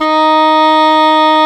WND OBOE D#4.wav